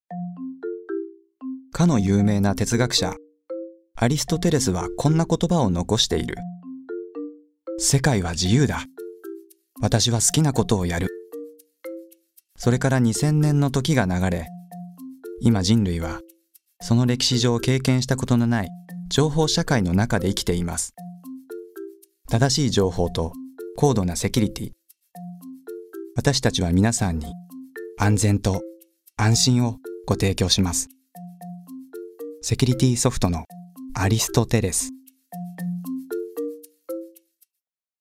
出⾝地・⽅⾔ 宮城県・東北弁
ボイスサンプル